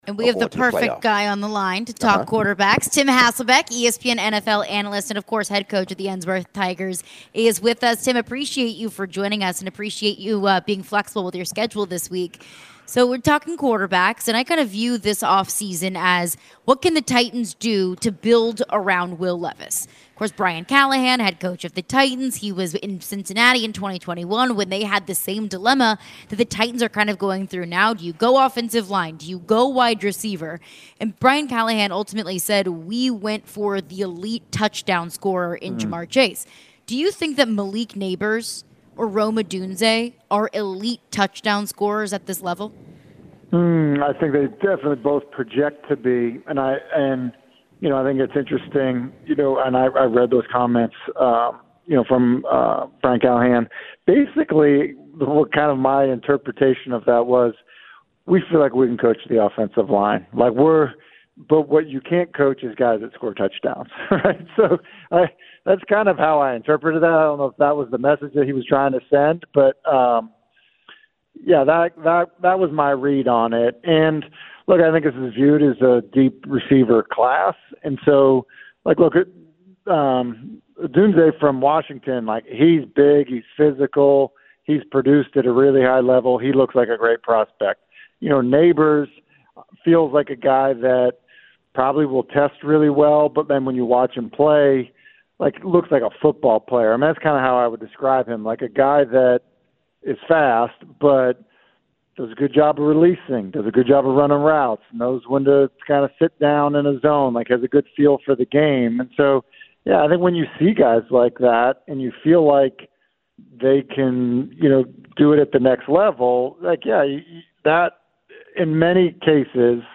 the guys chat with ESPN NFL analyst about the Titans and the upcoming draft. Tim answered if a couple of WRs are elite touchdown scorers.